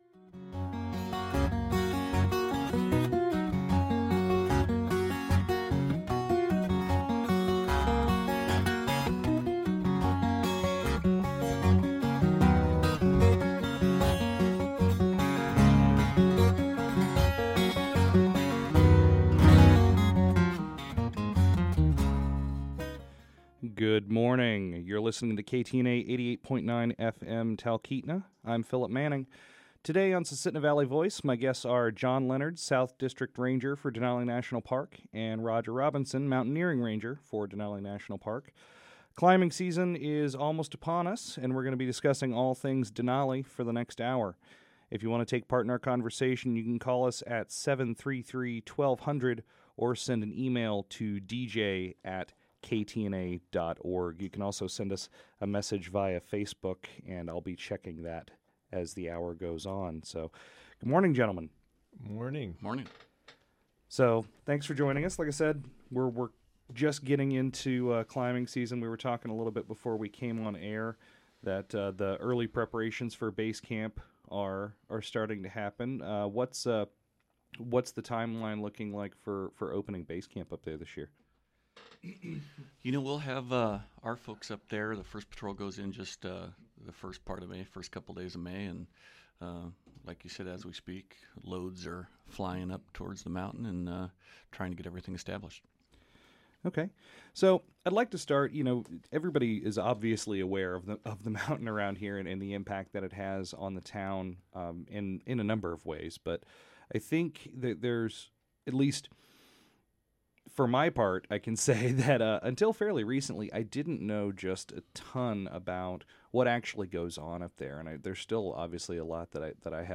Su-Valley Voice airs live every-other Wednesday at 10:00 am.